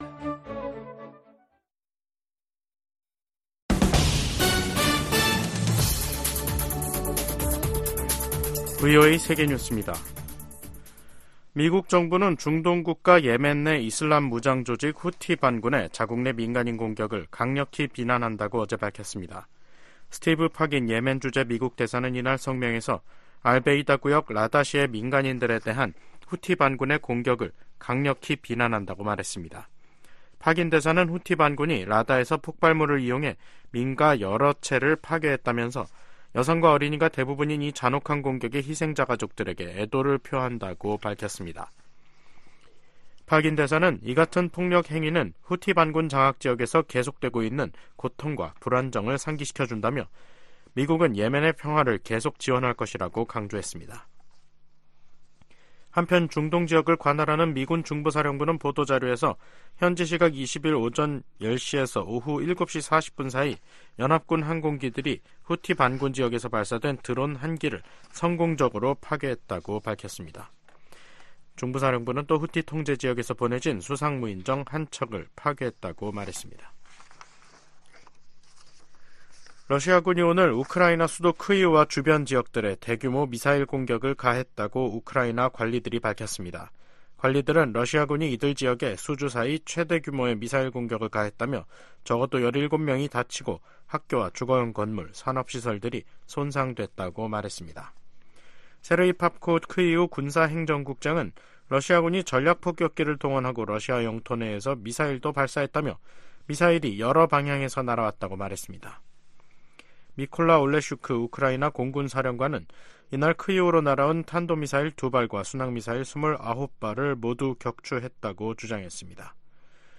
VOA 한국어 간판 뉴스 프로그램 '뉴스 투데이', 2024년 3월 21일 2부 방송입니다. 북한이 영변 핵시설에서 핵탄두 소형화에 필수적인 삼중수소 생산 시설을 가동 중이라는 위성사진 분석 결과가 나왔습니다. 북한이 러시아와 관계를 강화하면서 국제 질서를 위협하고 있다고 주한미군사령관이 지적했습니다. 블라디미르 푸틴 러시아 대통령의 5연임이 확정되면서 북한과 러시아 밀착에 탄력이 붙을 것이란 전망이 나옵니다.